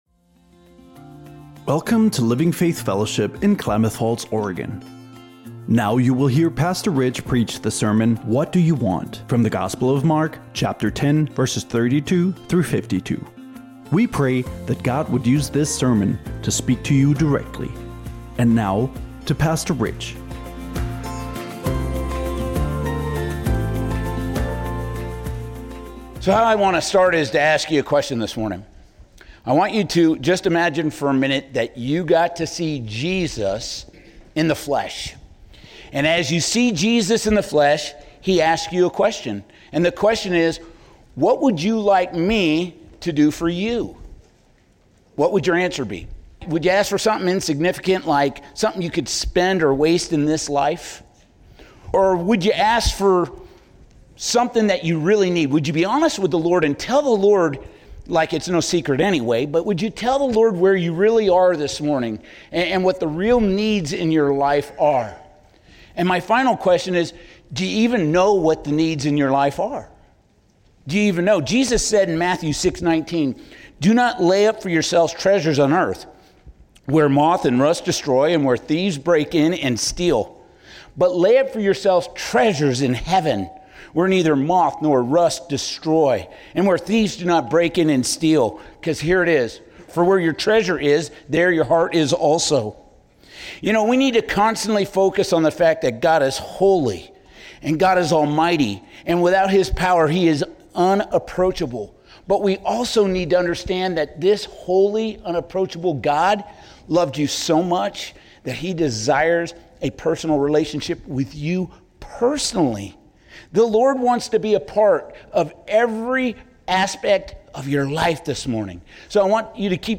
37:00 Download WATCH LISTEN SERMON SERMON NOTES Listen on Podcast Apple Podcasts Spotify Amazon Music